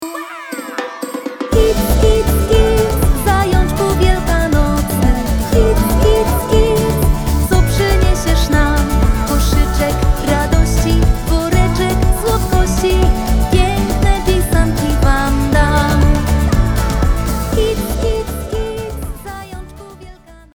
wesoła piosenka z pokazywaniem
Wesoły Utwór na Wielkanoc